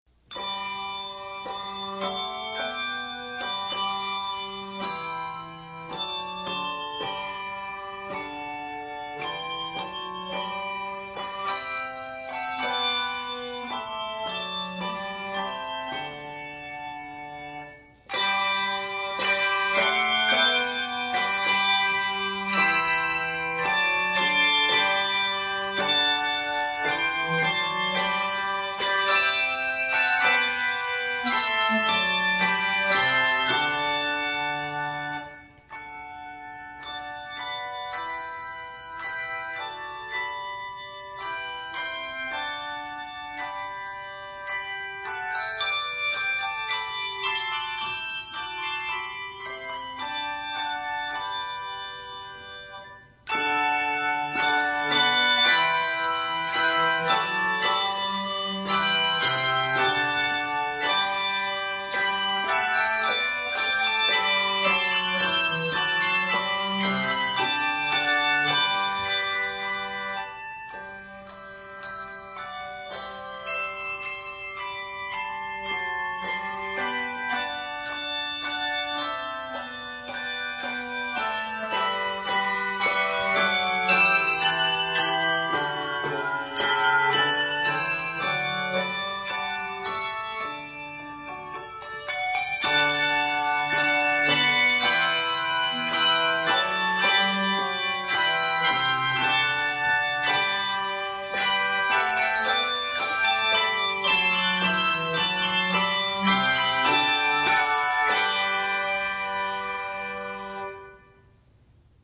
An easy arrangement of the majestic chorus